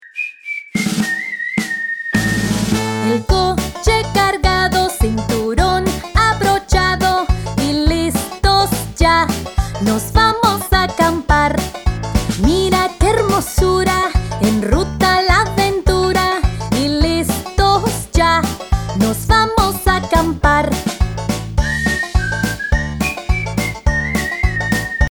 This lively Spanish children’s song